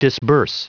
Prononciation du mot disburse en anglais (fichier audio)
Prononciation du mot : disburse